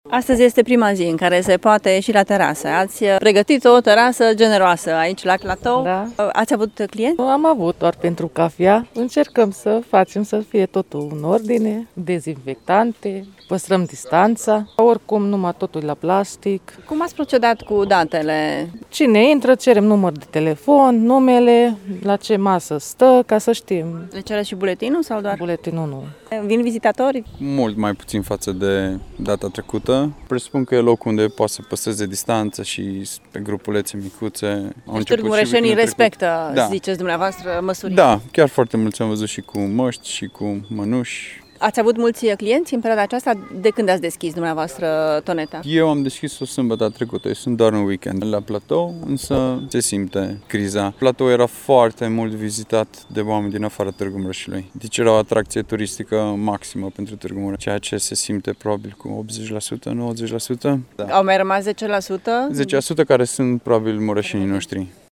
La Platoul Cornești, numărul turiștilor a scăzut cu circa 90%, spun unii comercianți: